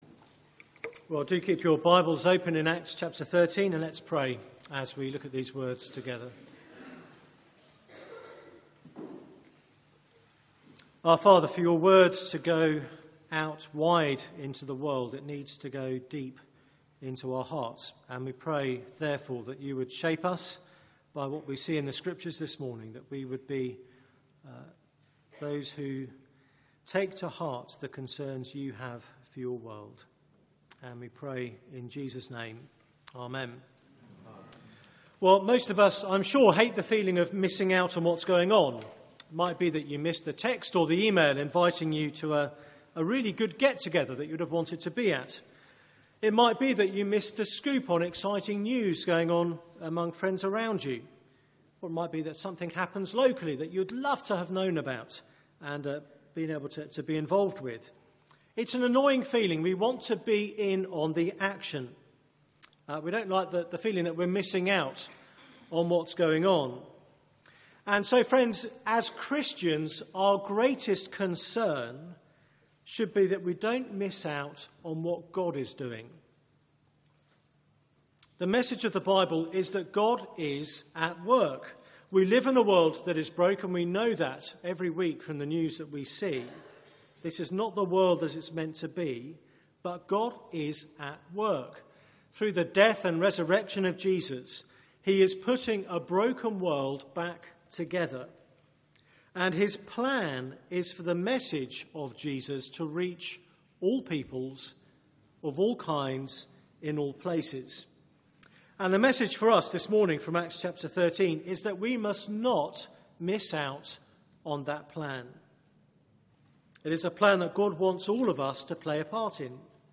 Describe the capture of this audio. Media for 9:15am Service